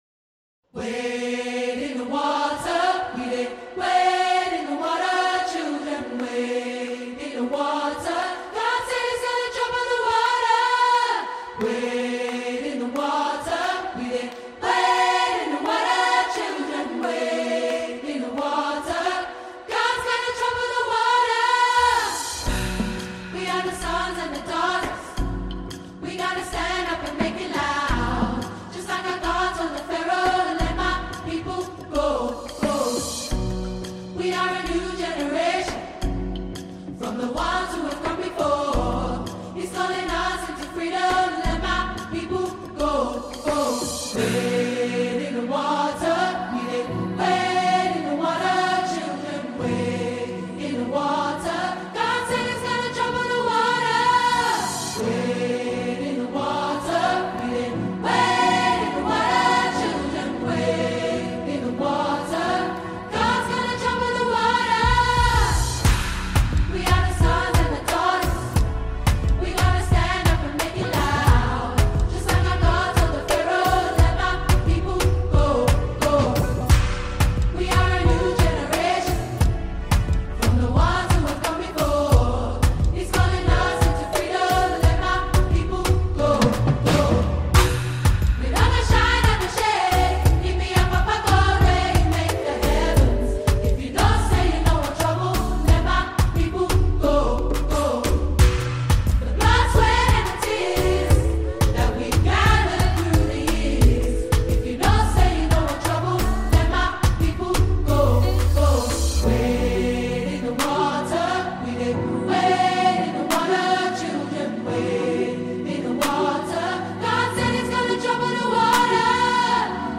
Gospel music group
Gospel spirit-filled track